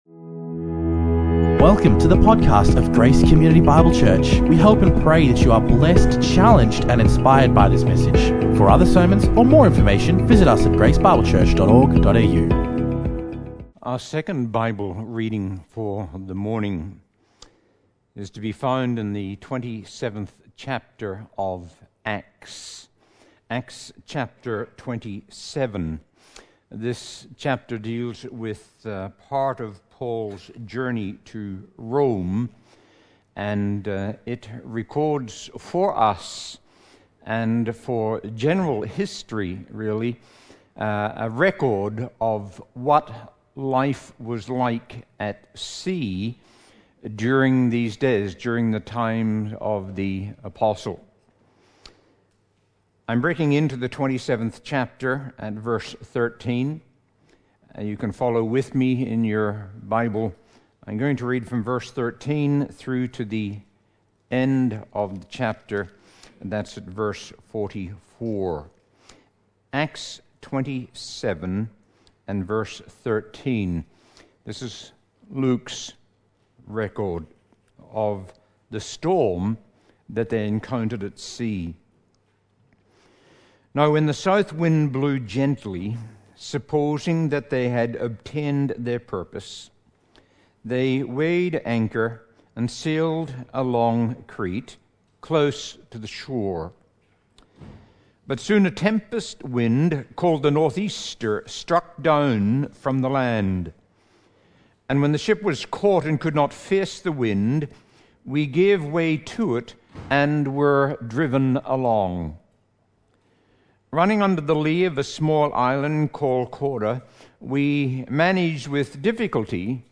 Guest preacher
recorded live at Grace Community Bible Church